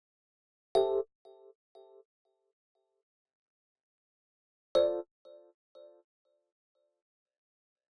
29 ElPiano PT4.wav